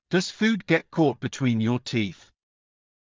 ﾀﾞｽﾞ ﾌｰﾄﾞ ｹﾞｯ ｺｳﾄ ﾋﾞﾄｩｳｨｰﾝ ﾕｱ ﾃｨｰｽ